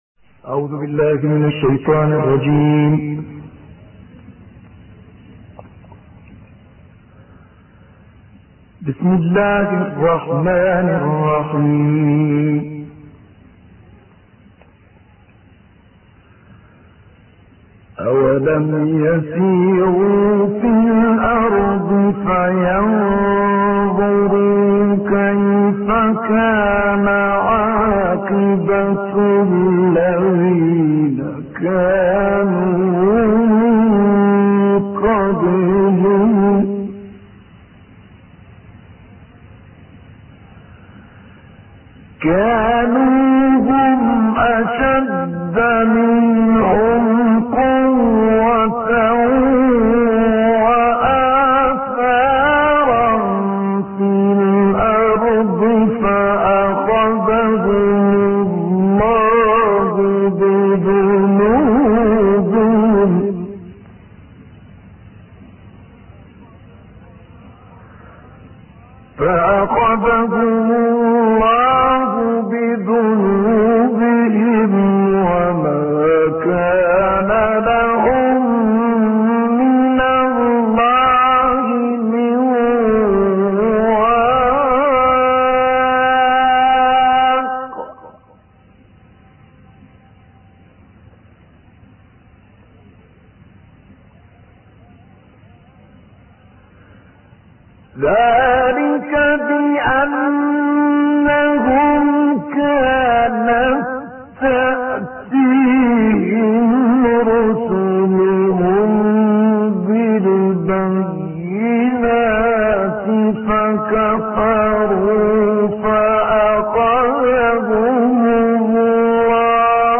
دانلود قرائت سوره غافر آیات 21 تا 40 - استاد طه الفشنی
قرائت-سوره-غافر-آیات-21-تا-40-استاد-طه-الفشنی.mp3